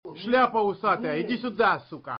смешные
голосовые